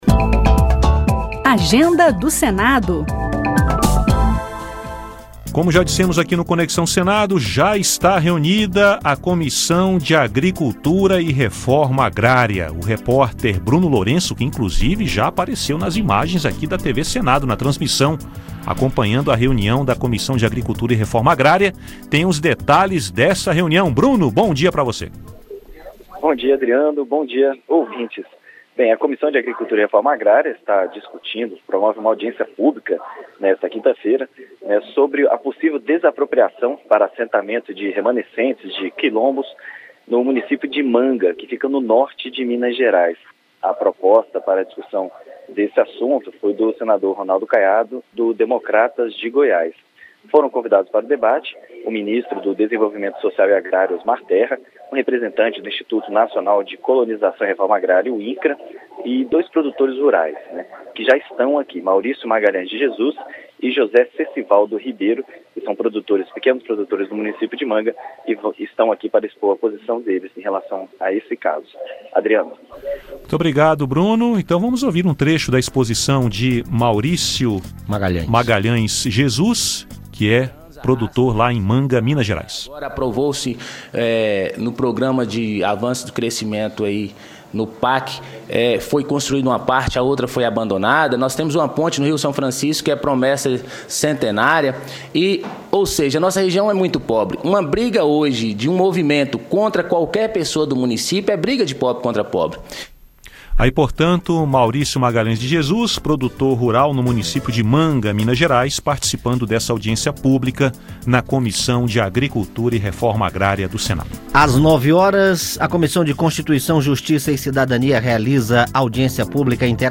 Confira a agenda do Senado Federal para esta quinta-feira (30), com informações dos repórteres da Rádio Senado.